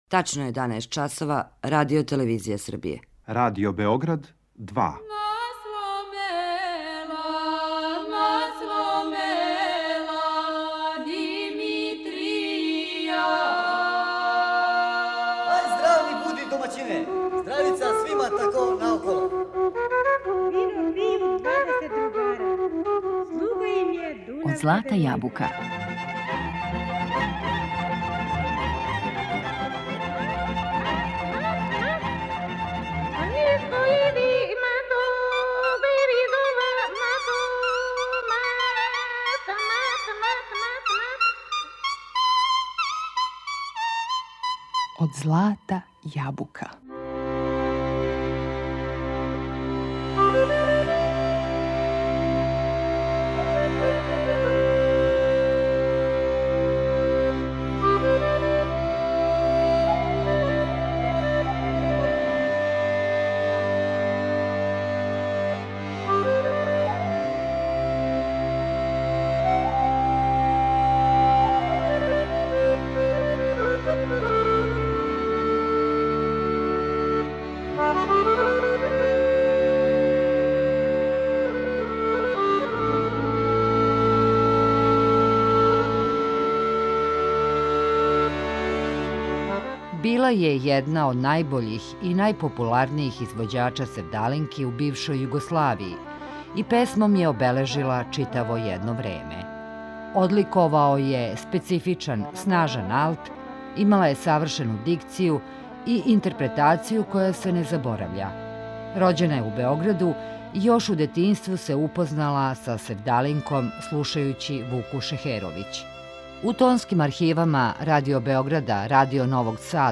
Одликовао ју је специфичан, снажан алт, имала је савршену дикцију и интерпретацију која се не заборавља.
севдалинки